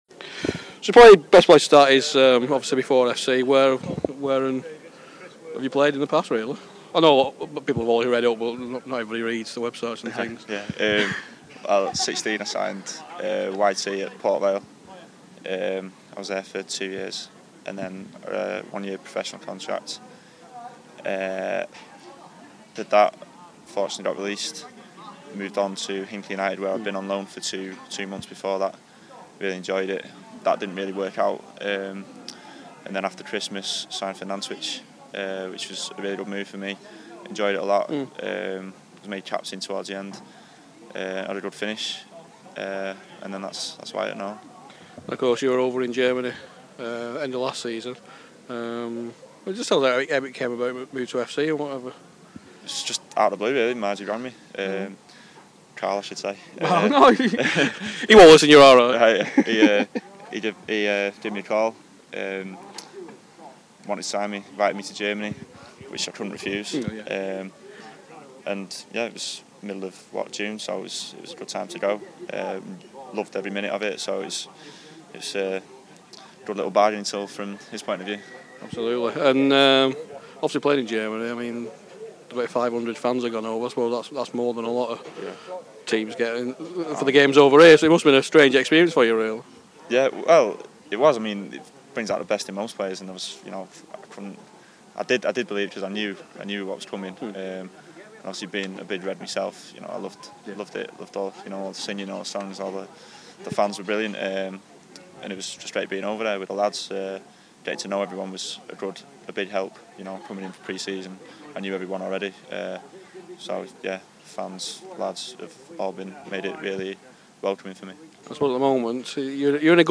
Pre Season Interview